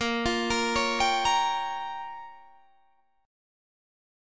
A#m6 chord